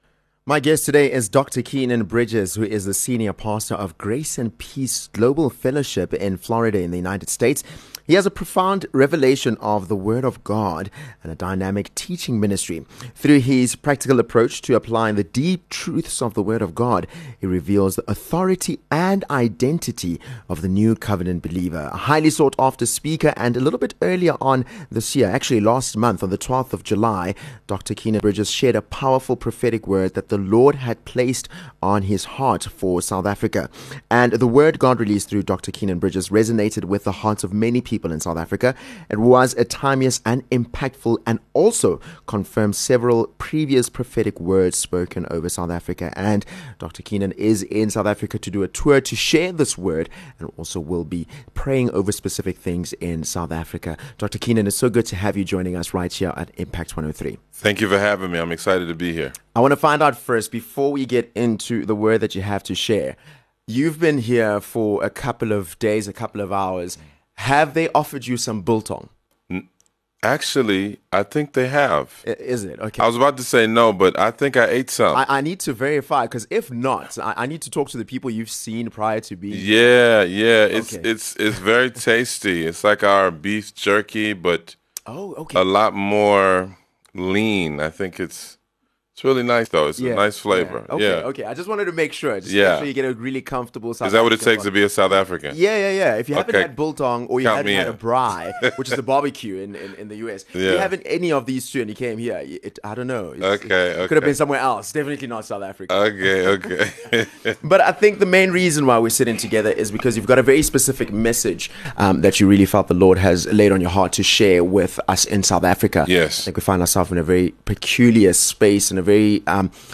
Full Interveiw